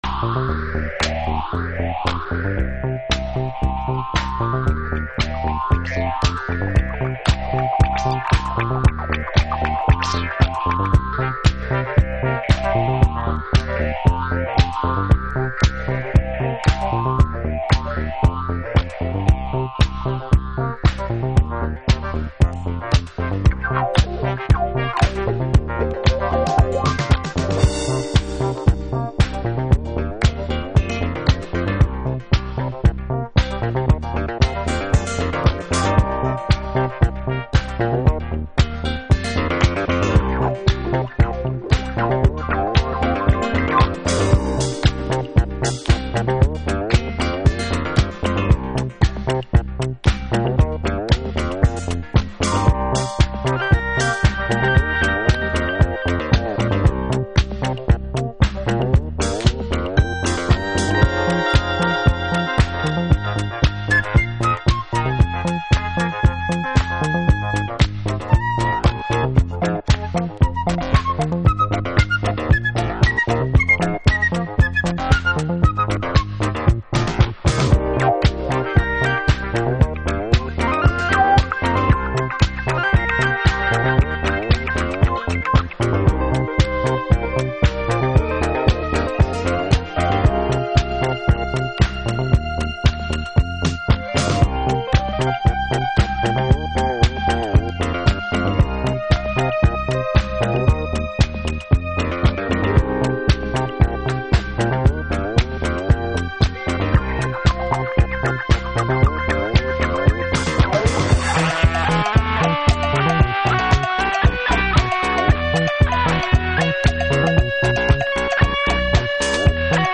Alt Disco / Boogie
ダンスフロアへの愛情と独自の文脈が生み出すハウスとディスコの狭間、タイトルが示すようなメタフォリカルに蠢く揺らぎ。